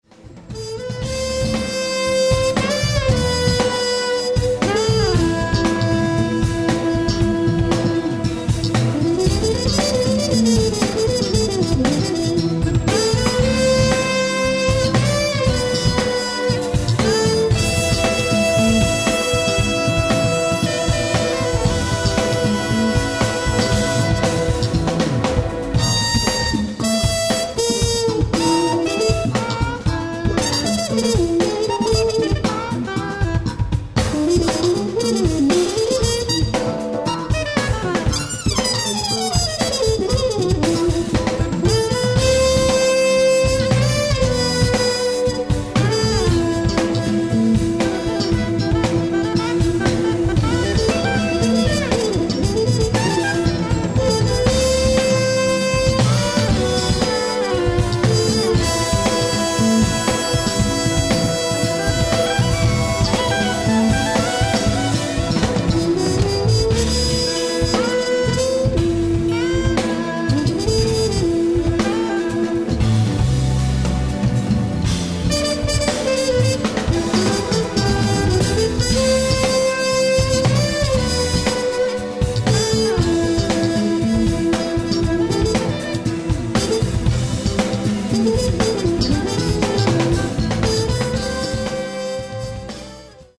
ライブ・アット・マルシアック・ジャズ、マルシアック、フランス
※試聴用に実際より音質を落としています。